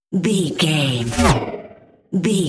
Pass by sci fi fast
Sound Effects
Fast
futuristic
intense
car
vehicle